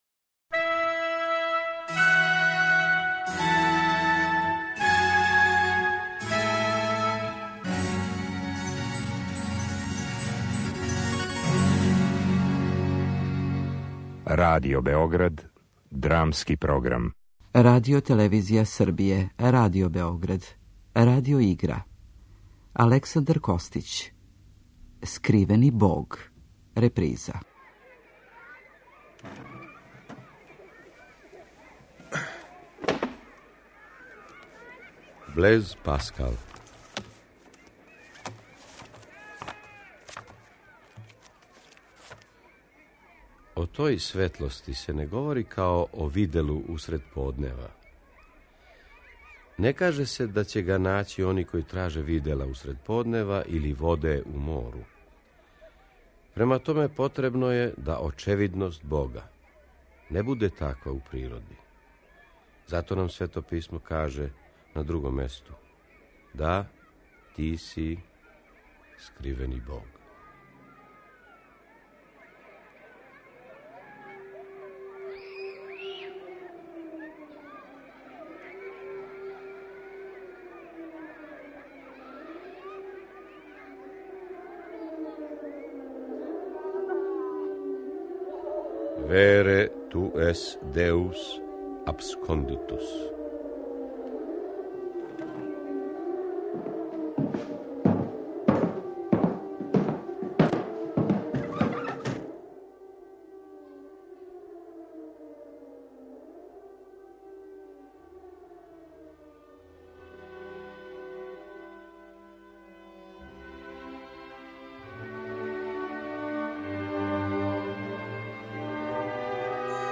Радио игра